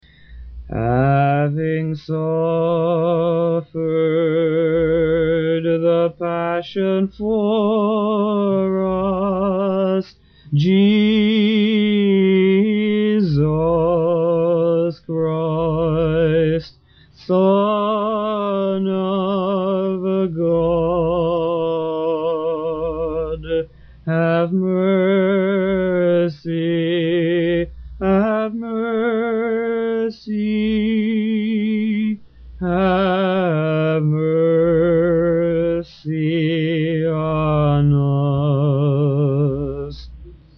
Spiritual songs > Hymns of the Great Fast >
In the Byzantine Catholic Church, this paraliturgical hymn is sung immediately after services on the weekdays of the Great Fast; it is not sung on Saturdays or Sundays. It is often sung three times, in English, Slavonic or both: once by the priest, and twice more by the faithful.